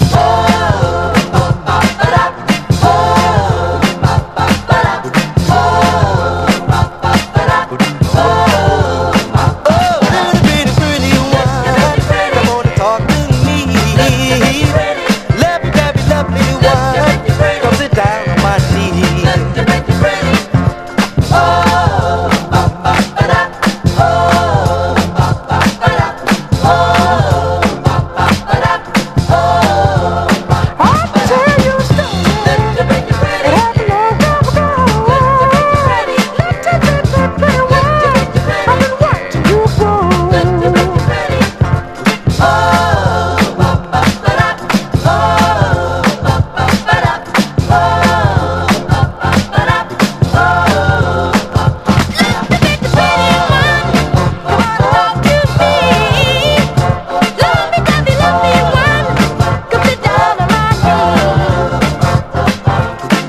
SOUL / SOUL / 70'S～ / DISCO / SAMPLING SOURCE / PHILLY SOUL